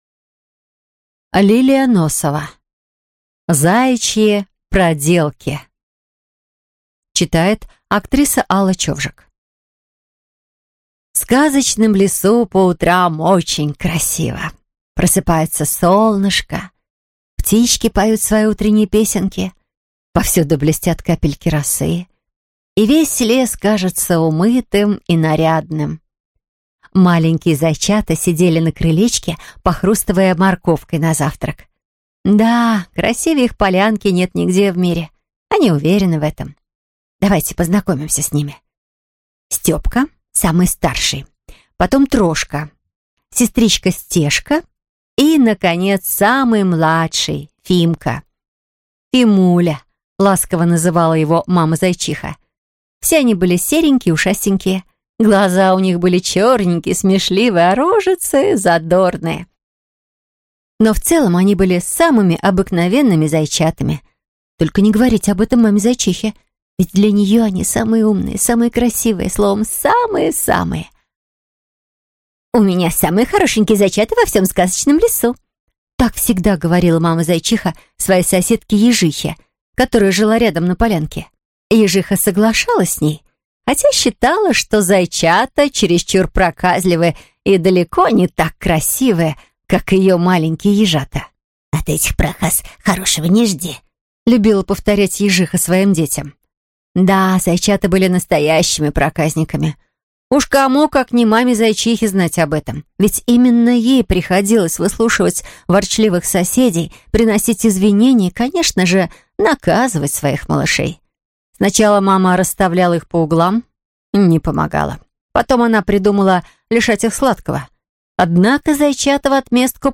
Аудиокнига Заячьи проделки | Библиотека аудиокниг